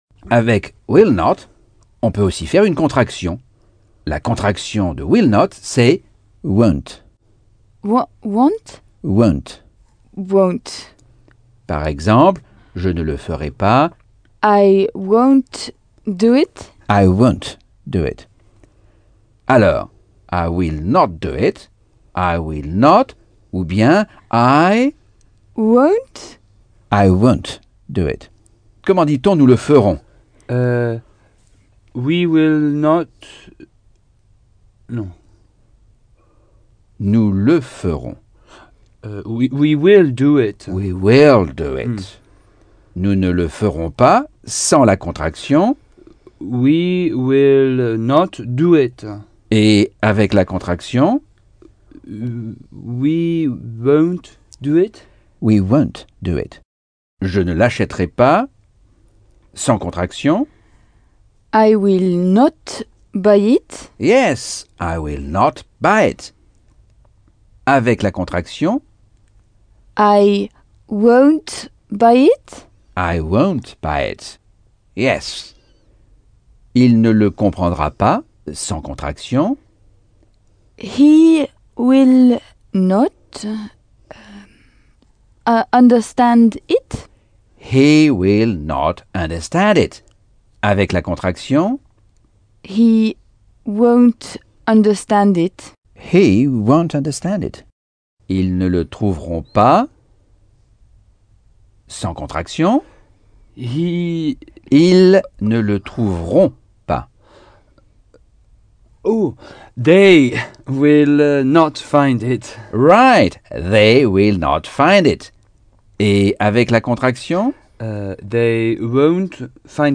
Leçon 12 - Cours audio Anglais par Michel Thomas